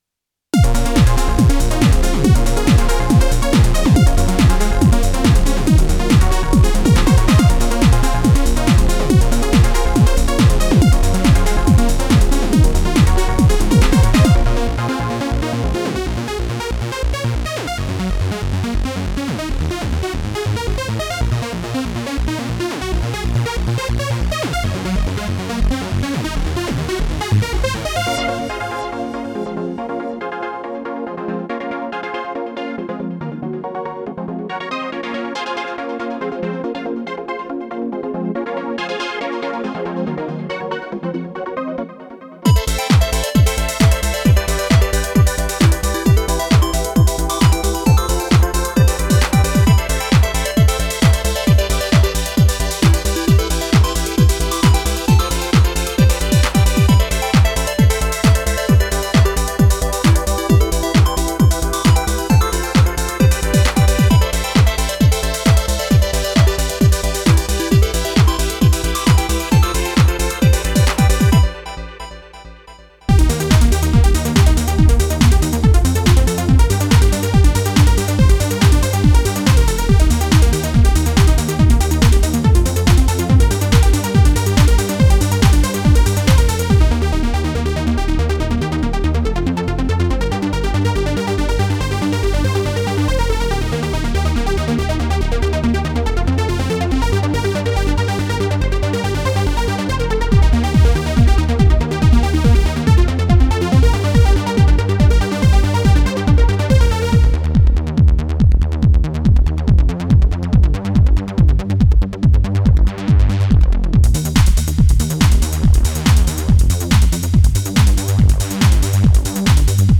All the power of the popular Electribe EM-1 rompler/groovebox, combining PCM synth samples, acoustic instruments, and drum samples meticulously cloned into your Novation Circuit, including the best factory sounds and patterns.
Includes 64 samples of their best drum sounds.
128 patches and 52 sessions featuring the most popular genres of the late 90s and early 2000s, including Trance, House, Techno, Electro, Drum & Bass, Nu Skool Breaks, and more.